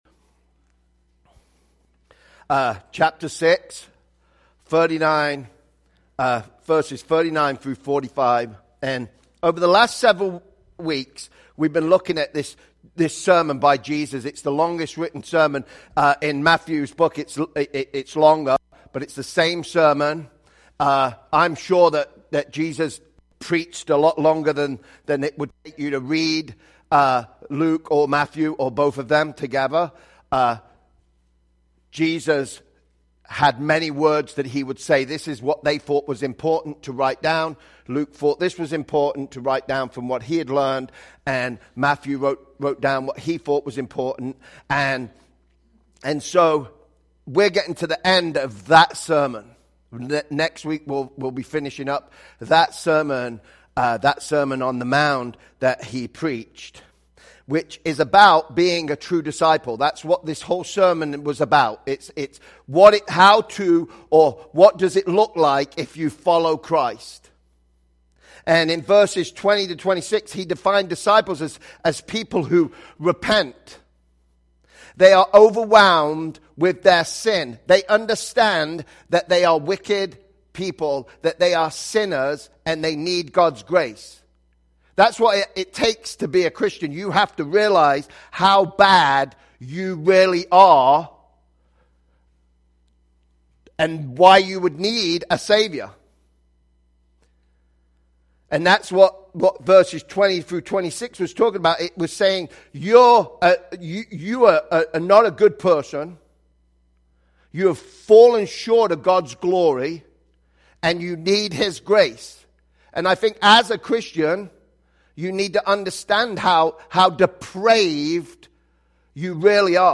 Sermons by Life815